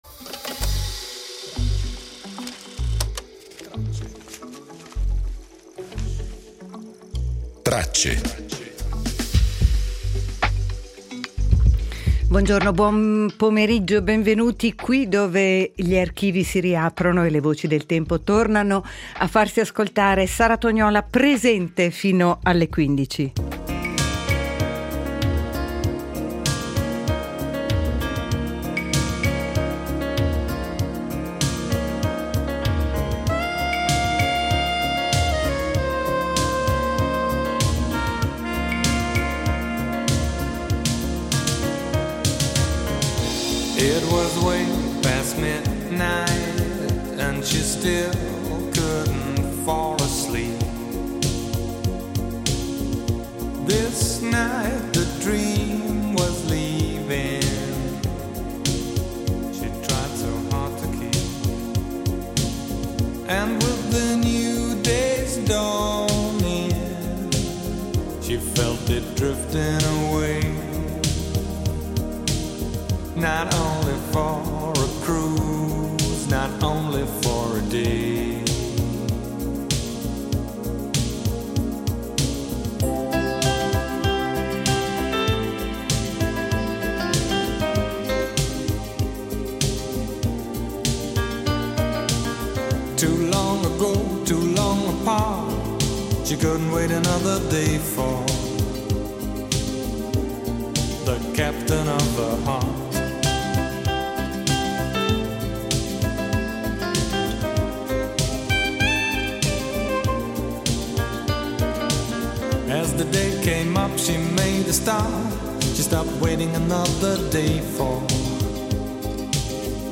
La puntata odierna di Tracce attraversa quattro momenti emblematici, ripercorsi grazie alle voci dell’archivio RSI. Napoleone: ricordiamo l’incoronazione a re d’Italia del 1805, un gesto carico di simboli e costruzione del potere. Terri Schiavo: riviviamo il caso che nel 2005 ha acceso il dibattito mondiale sul fine vita e sulle decisioni legali e mediche.